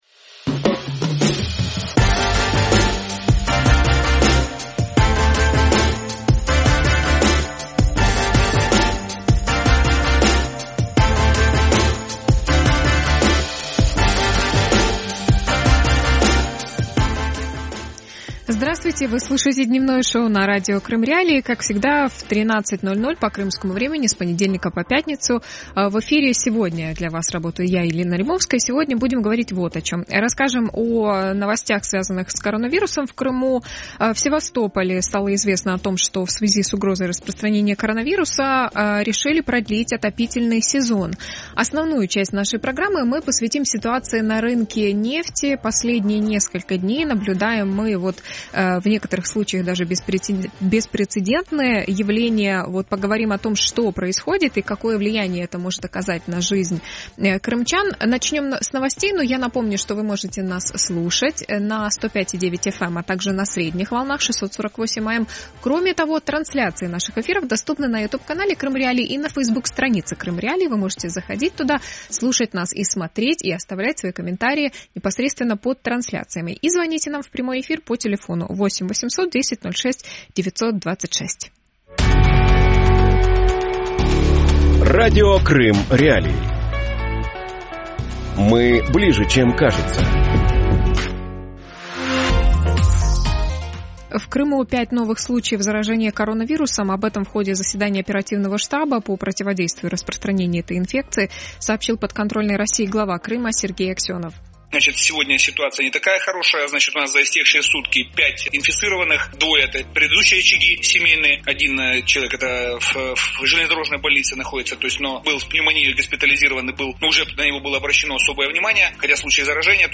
Нефть, Россия и Крым | Дневное ток-шоу